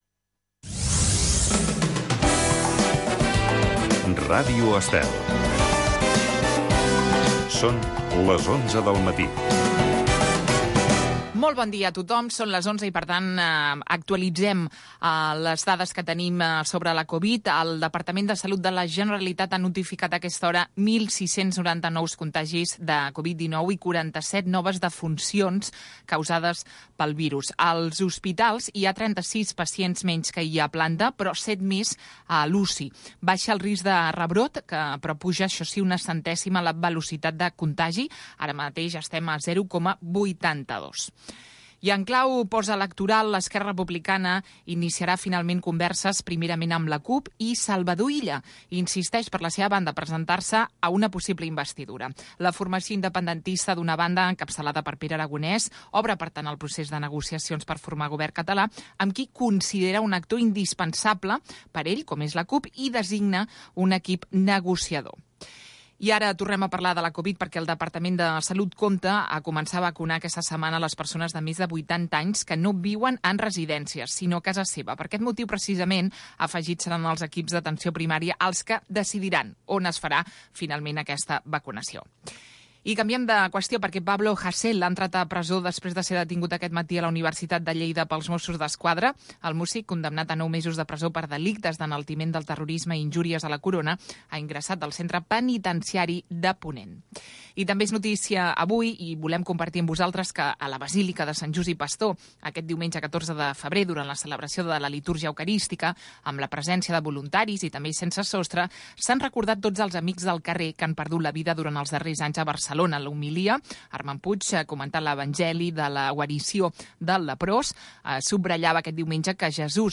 Espai amb d'entrevistes als protagonistes més destacats de cada sector, amenitzat amb la millor música dels 60, 70, 80 i 90